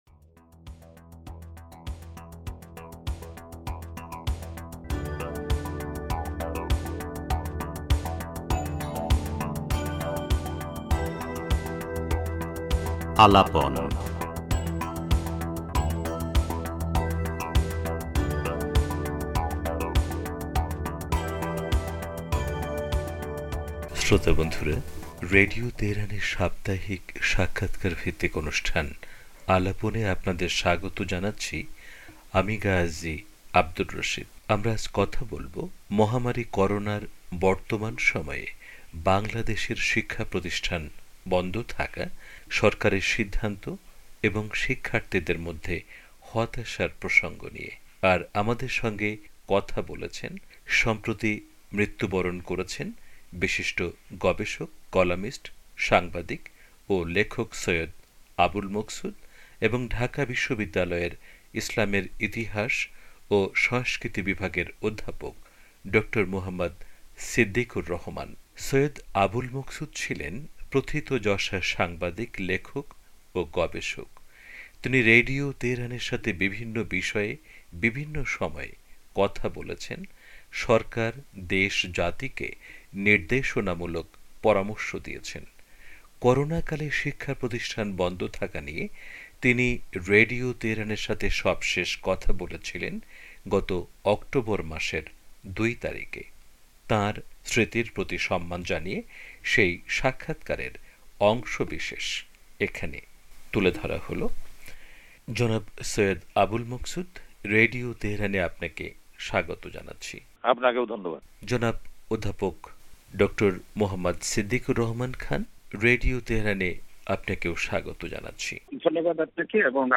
সাক্ষাৎকারে